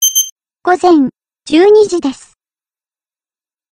音声で時報をお知らせします。